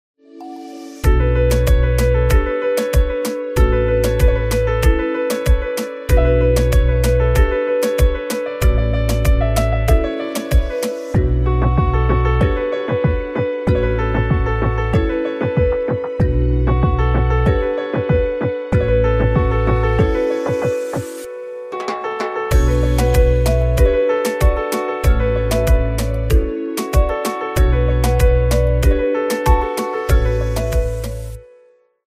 Punjabi Ringtone